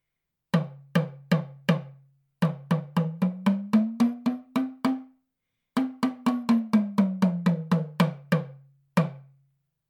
Talking Drum TAMA from Mali KANGABA workshop
このサイズでも本格仕様、軽やかに小気味よく響きます。